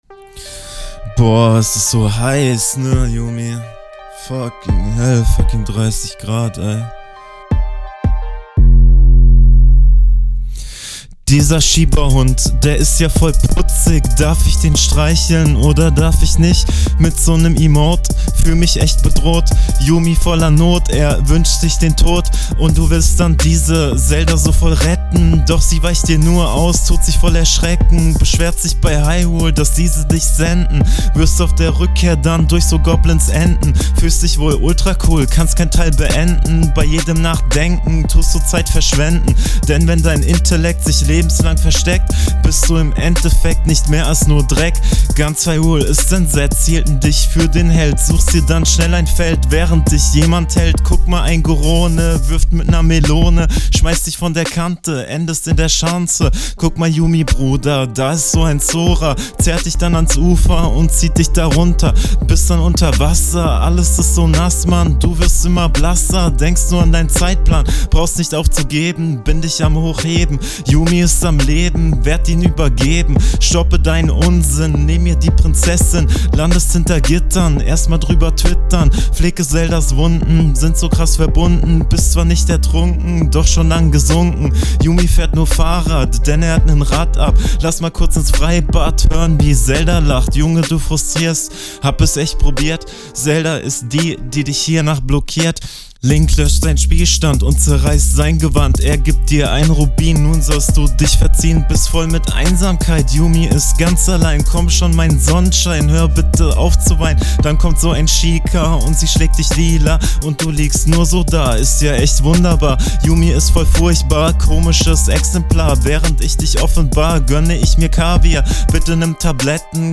Popschutz gleiches Problem.